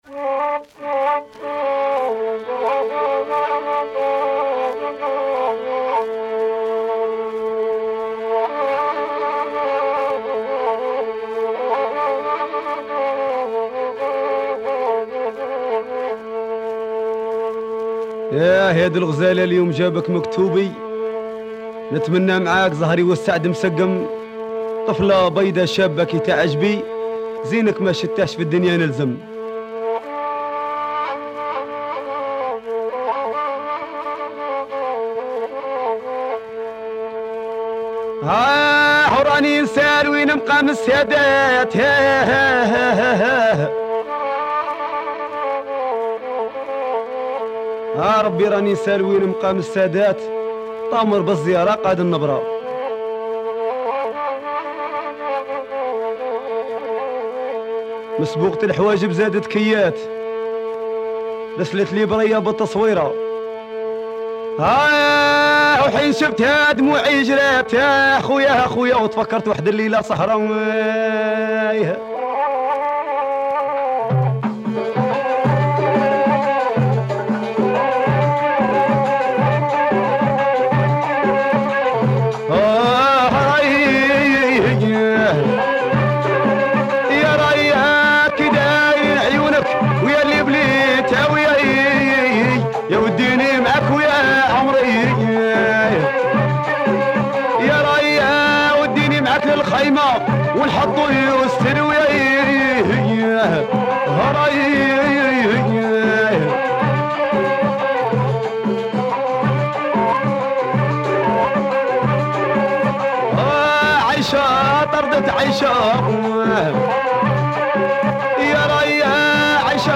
proto rai